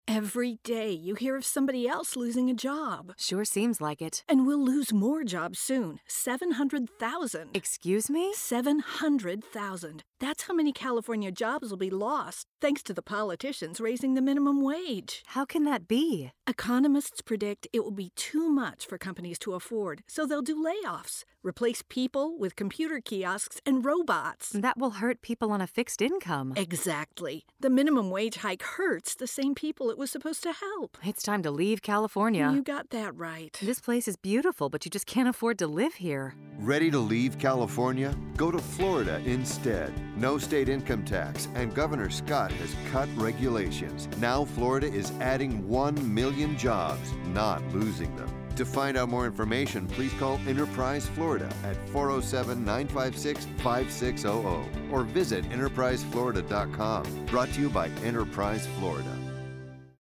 A controversial job-recruitment radio spot has preceded him.
The argument of the one-minute ad is clear: California’s heavily debated decision to increase its minimum wage to $15 by 2022 is a bad idea.